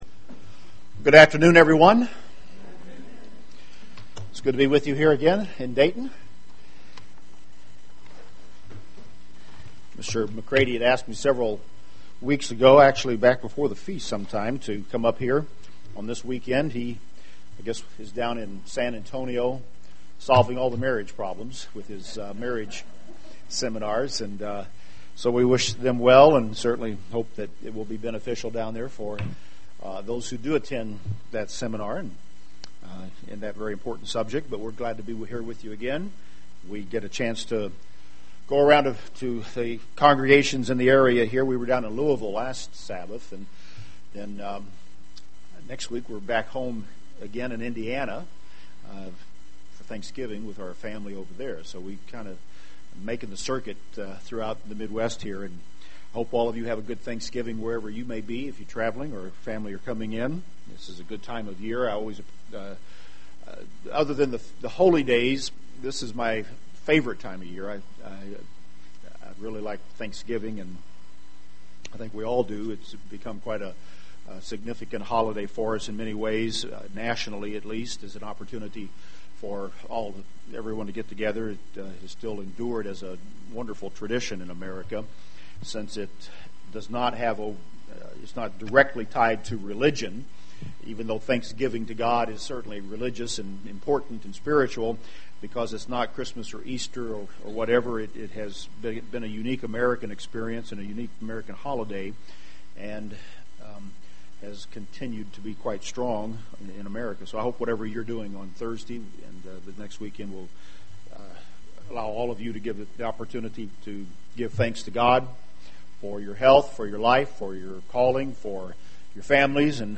Indulge into the book of Philemon and discover three lessons of Trust UCG Sermon Transcript This transcript was generated by AI and may contain errors.